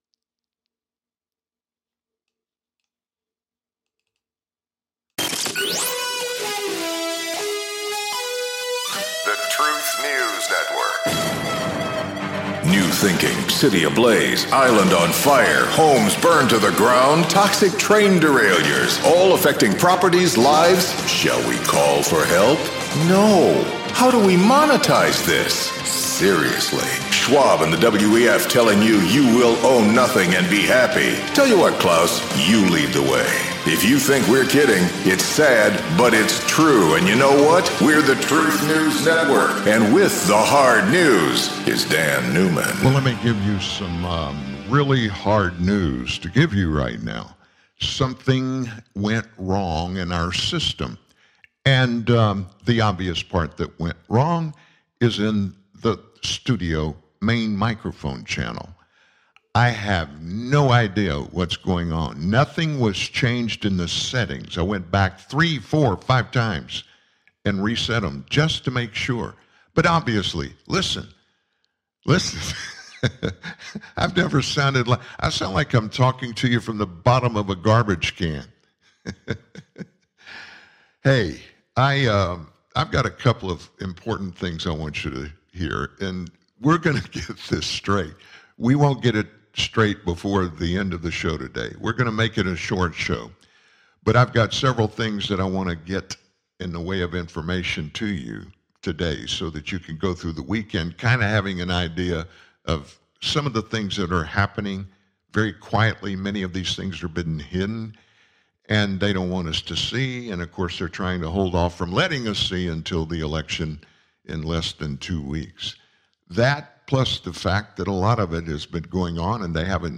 Hillary Clinton was out over the weekend selling the fact that our government MUST cancel Section 230 to give the federal government total control over Americans' speech! (You'll hear Hillary spell that out in her own words).